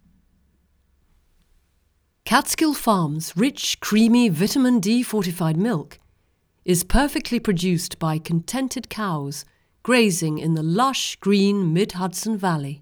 OK, here we go: a new test recorded on a different machine:
Much better. I applied Audiobook Mastering tools and it passes ACX technical testing.
The first two seconds is not silence or Room Tone. I can hear shuffling, adjusting clothing, flipping papers, etc. All that is forbidden for that two seconds.
The microphone spacing is too close.
The “P” in Perfectly Produced is explosive.
I have expressive P sounds, so I use B. This might also help suppress a slight sharpness to the sound. Almost Essing, but not quite.
I show a low pitched tone probably computer fan sound behind the performance.